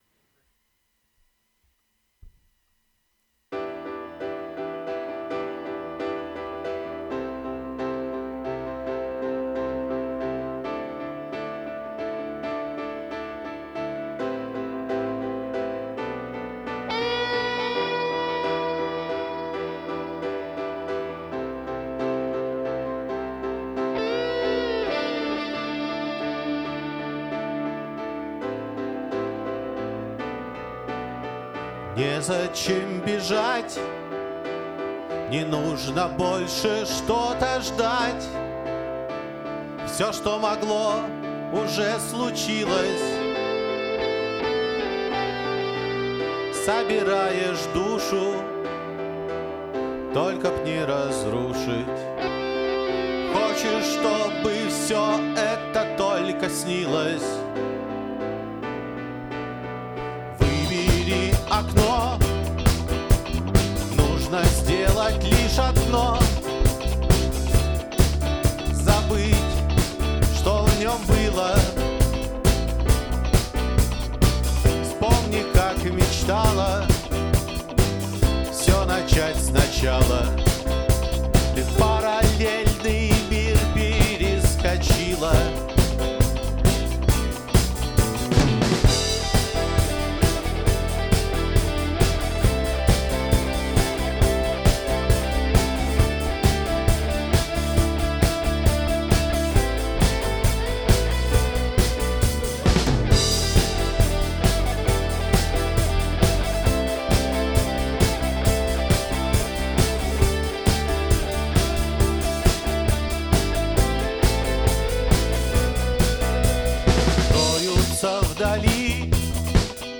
Демо записи
живой звук